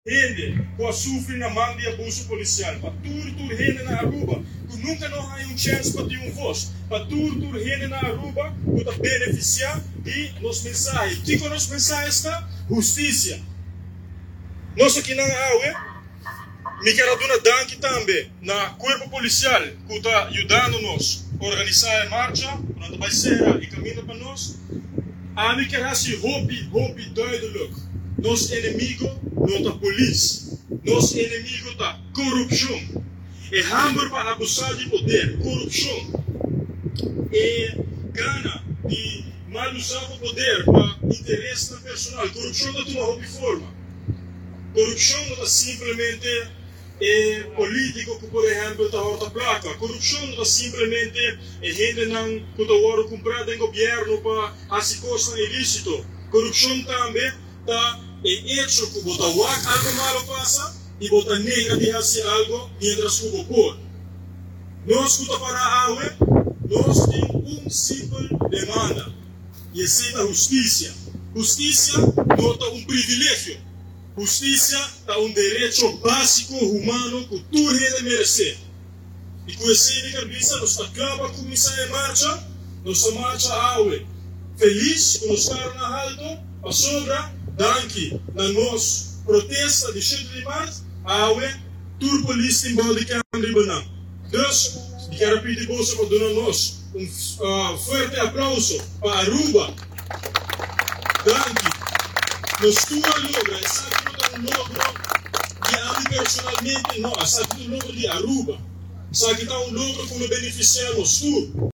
Dilanti edificio di Corte di husticia pa ta presiso dilanti di sra Husticia a indica cu kier ta bon cla no ta polis ta pueblo su enemigo, pero nos enemigo ta corupcion, e hamber pa abusa di poder, e gana di mal uza poder pa interesnan personal, “ corupcion “  ta hunga den hopi forma, corupcion no ta simplemente e politico cu ta horta placa, corupcion no ta simplemente e hende nan cu ta wordo cumpra den gobierno pa haci cosnan ilicito, pero corupcion ta tambe  cu bo ta wak algo pasa y bo ta nenga di haci algo siendo cu bo por.